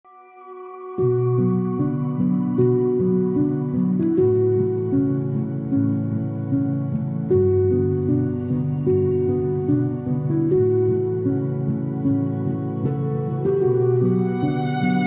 Eco Sustainable Logo Intro sound effects free download